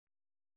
♪ hāsaṛe galu